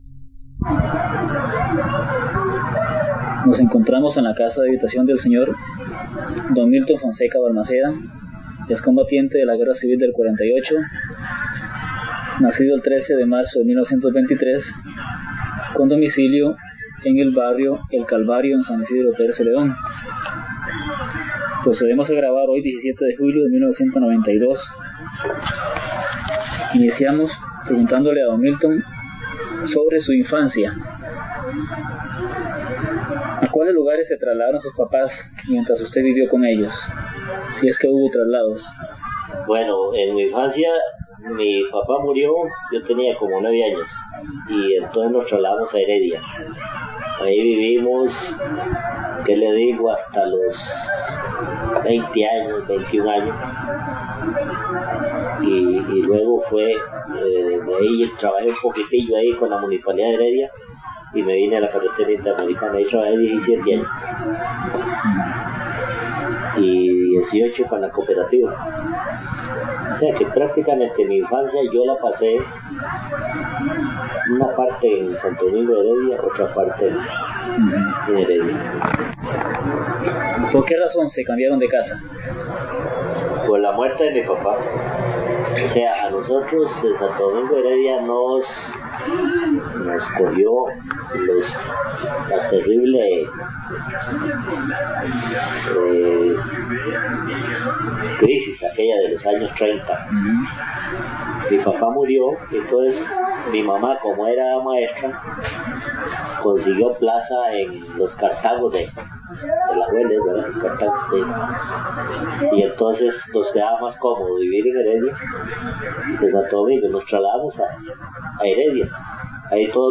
Entrevista
Notas: Casete de audio y digital